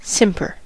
simper: Wikimedia Commons US English Pronunciations
En-us-simper.WAV